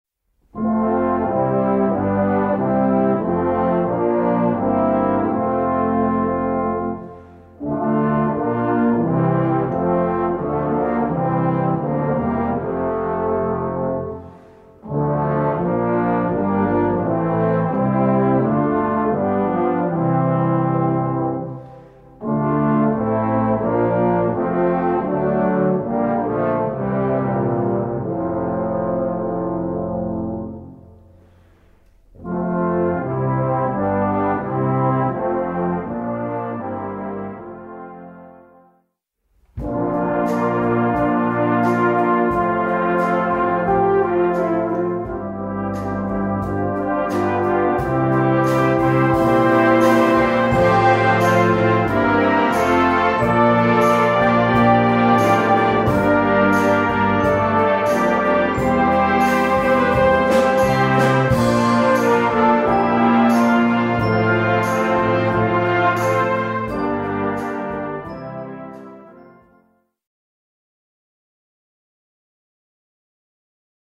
- ab lib. mit Chor
Gattung: Weihnachten
Besetzung: Blasorchester